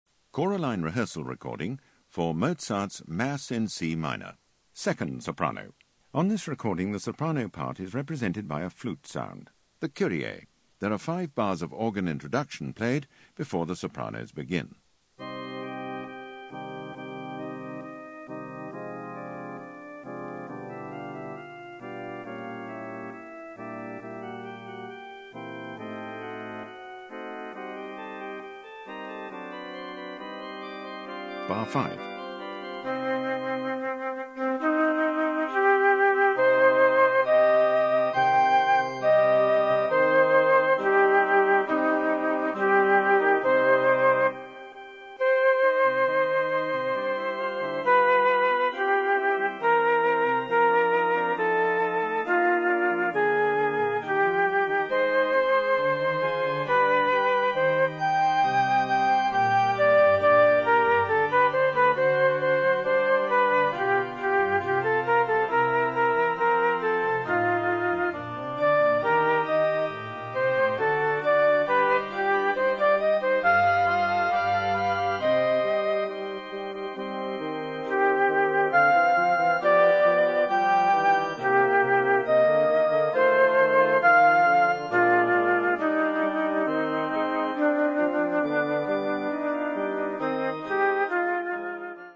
Soprano 2
High Quality made by BBC Sound Engineer
Easy To Use narrator calls out when to sing
Don't Get Lost narrator calls out bar numbers
Be Pitch Perfect hear the notes for your part